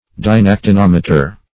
Search Result for " dynactinometer" : The Collaborative International Dictionary of English v.0.48: Dynactinometer \Dy*nac`ti*nom"e*ter\, n. [Gr.
dynactinometer.mp3